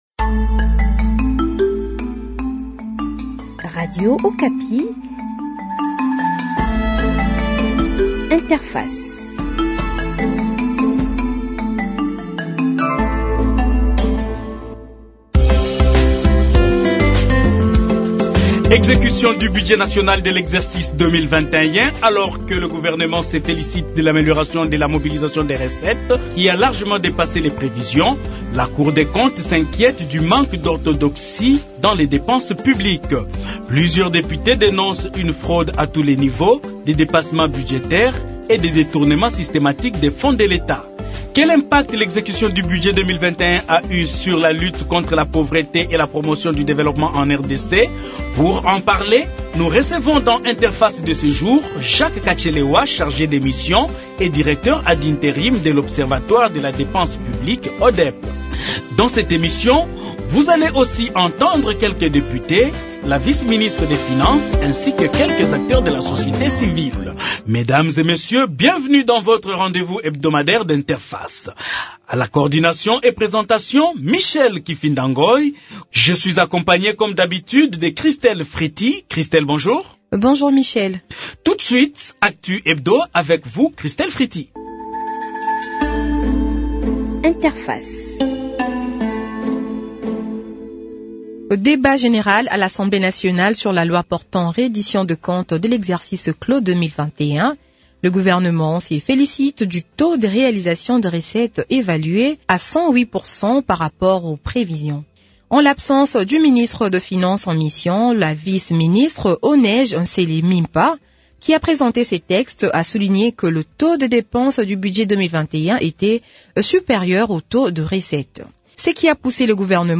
Dans cette émission, vous allez aussi entendre les députés Nsinngi Pululu, Solange Masumbuko et Deo Nkusu. vous alles aussi suivre la replique du gouvernement par la bouche de la vice-ministre des finances O'Neige Nsele sur les observations des députés.